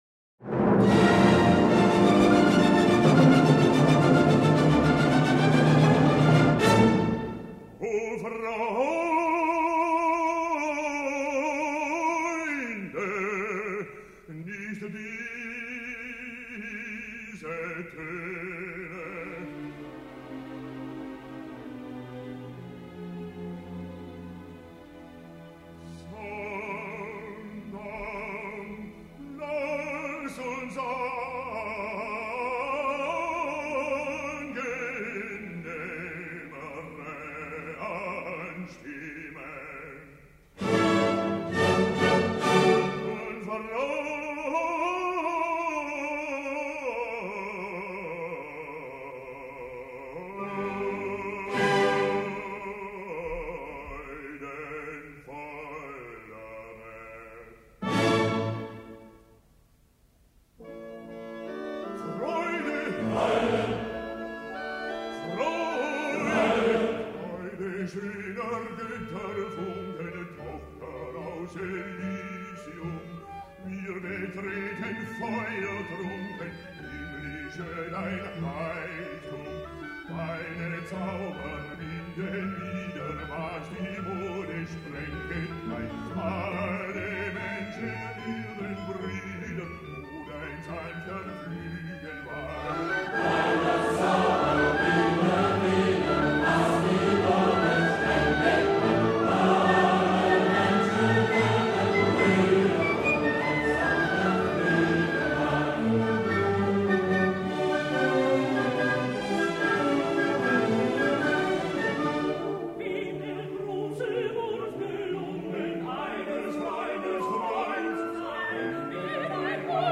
6. GOTTLOB FRICK (Bass)
Orchestre de la Société des concerts du Conservatoire
cond. by Carl Schuricht